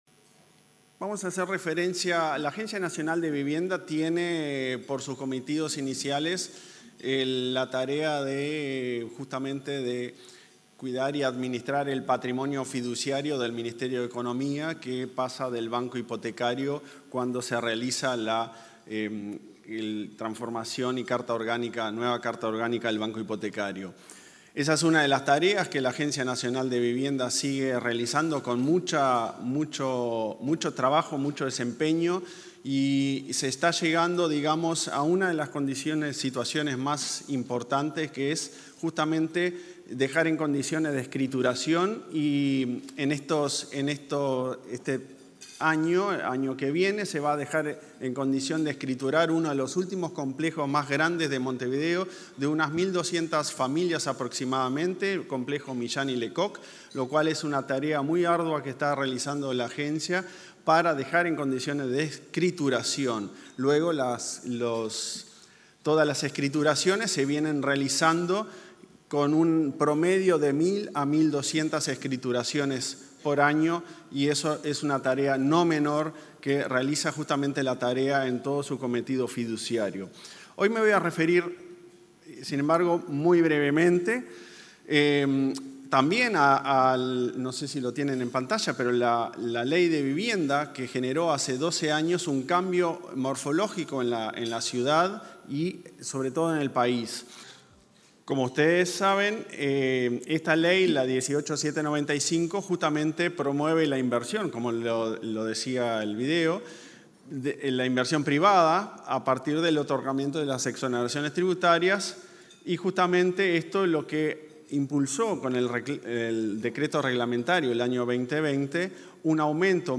Disertación de autoridades en desayuno de ADM
Disertación de autoridades en desayuno de ADM 22/08/2023 Compartir Facebook X Copiar enlace WhatsApp LinkedIn Los presidentes de la Agencia Nacional de Vivienda, Klaus Mill; el Banco Hipotecario del Uruguay, Casilda Echeverría, y el Banco de Seguros del Uruguay, José Amorín Batlle, disertaron, este martes 22, en un desayuno de trabajo de la Asociación de Dirigentes de Marketing (ADM).